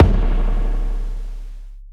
Live_kick_sub_2.wav